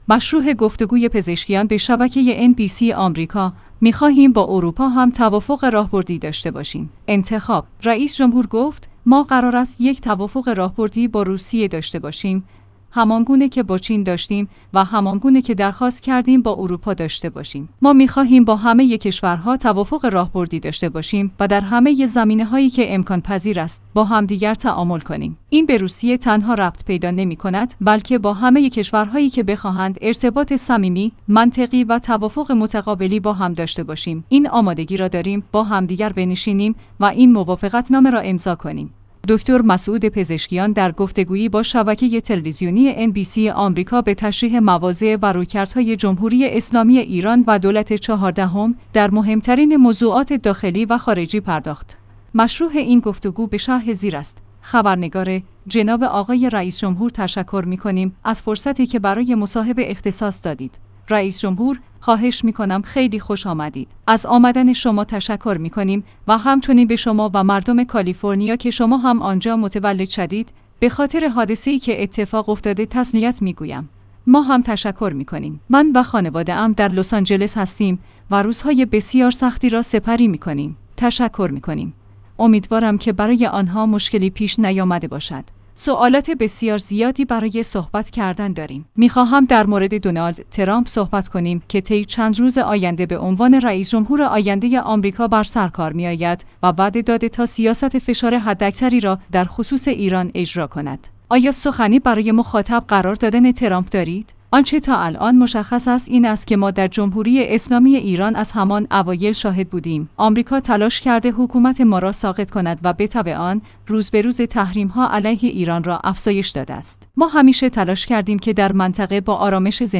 مشروح گفت‌وگوی پزشکیان به شبکه NBC آمریکا: می خواهیم با اروپا هم توافق راهبردی داشته باشیم